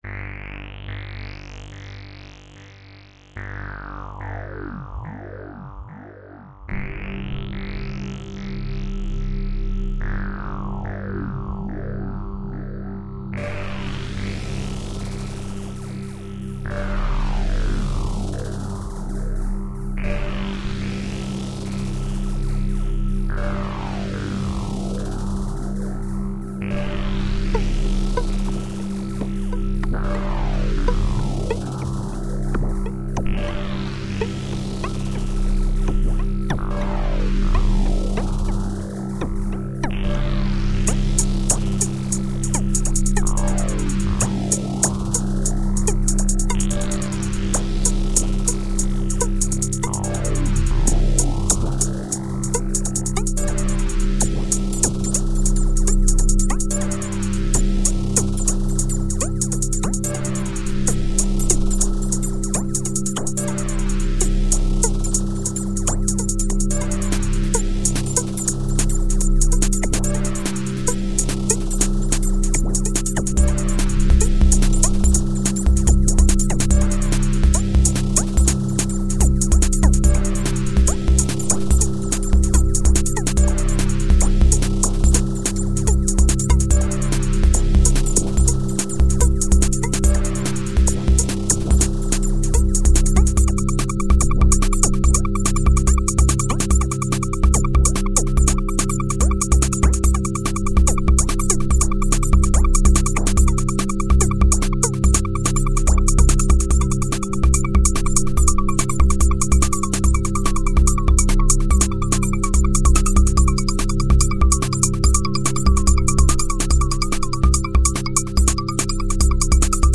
File under: New Electronica